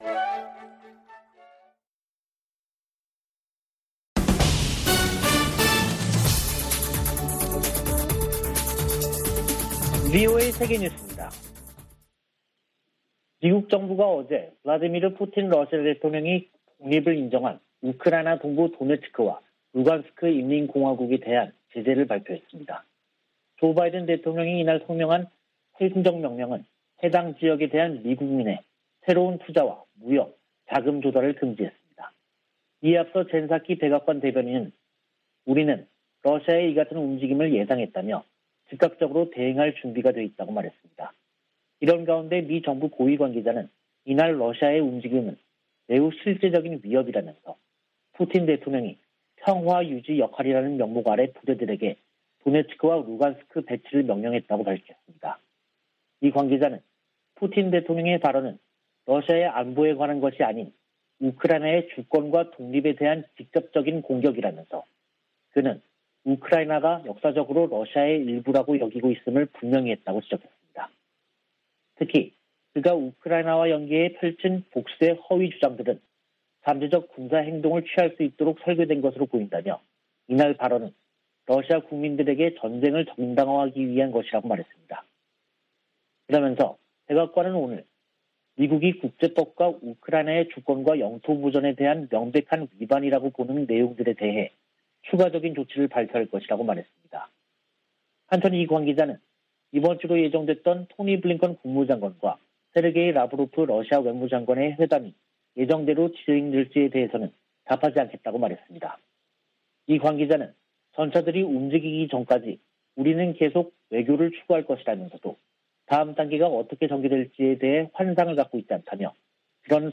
VOA 한국어 간판 뉴스 프로그램 '뉴스 투데이', 2022년 2월 22일 3부 방송입니다. 토니 블링컨 미 국무장관이 왕이 중국 외교부장과 북한 문제와 우크라이나 사태 등에 관해 전화협의했습니다. 조 바이든 미국 행정부가 러시아 군의 우크라이나 침공에 대응하는 조치를 구체화하는 가운데 한국 등 아시아 동맹국의 인도주의 지원 등이 거론되고 있습니다. 김정은 북한 국무위원장이 시진핑 중국 국가주석에게 친서를 보내 대미 공동전선 협력을 강조했습니다.